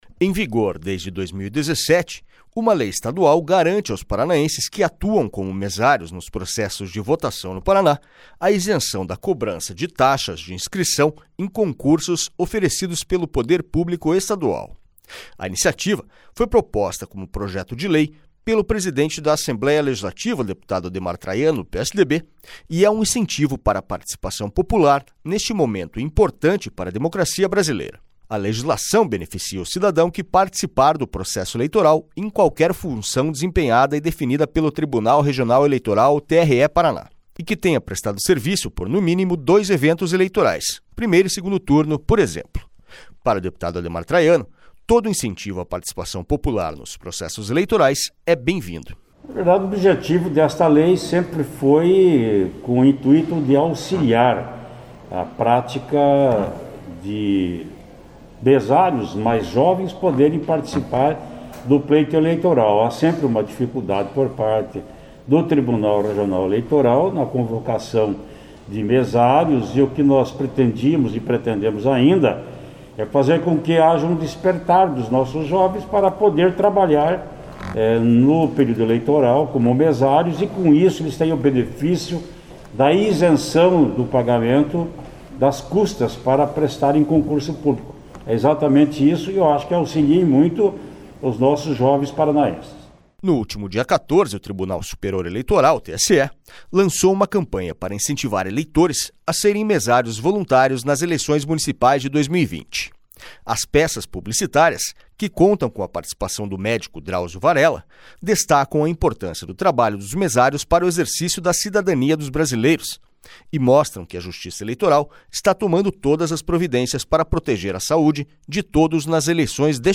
SONORA ADEMAR TRAIANO